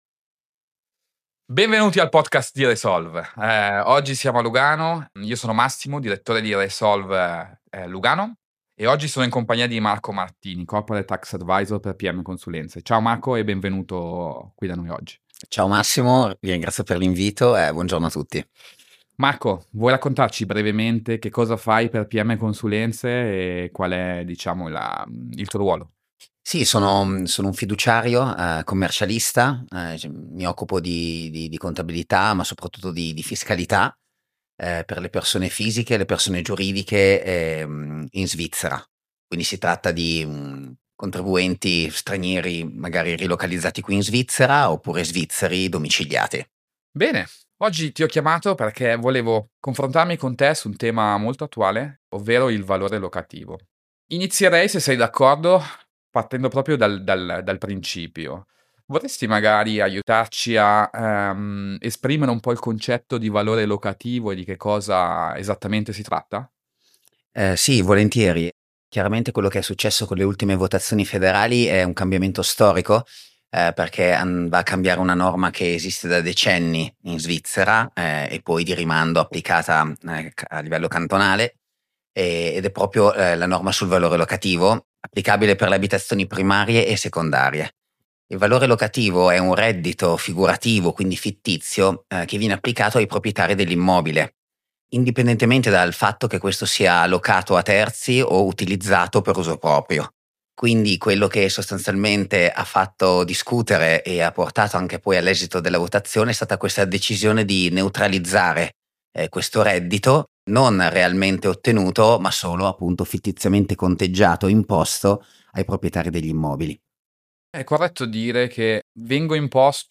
La conversazione affronta in dettaglio cosa cambia concretamente per i proprietari di abitazioni primarie e secondarie: la scomparsa del reddito figurativo elimina un onere fiscale, ma con esso anche le deduzioni per interessi ipotecari e spese di manutenzione.